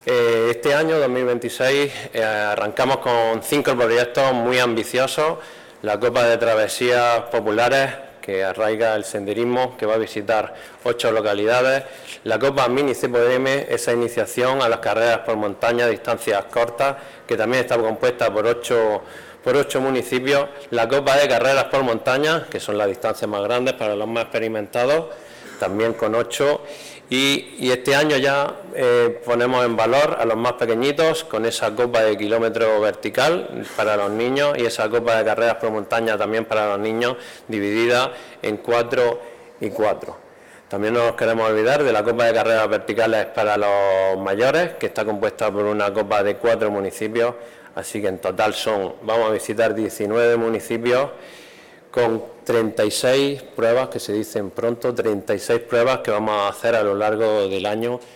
El Pabellón Moisés Ruiz ha acogido el acto de presentación de esta programación.